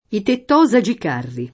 vai all'elenco alfabetico delle voci ingrandisci il carattere 100% rimpicciolisci il carattere stampa invia tramite posta elettronica codividi su Facebook tettosage [ tett 0@ a J e ] o tectosage [ tekt 0@ a J e ] etn. stor.